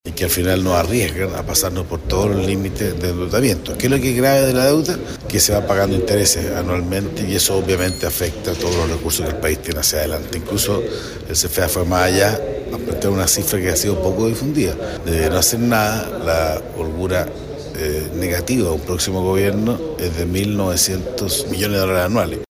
En la oposición, en tanto, el senador de la UDI, Juan Antonio Coloma, quien consultó a la Presidenta del Banco Central, Rosanna Costa, por esto, volvió a hacer hincapié en la base de la que partirá un próximo gobierno.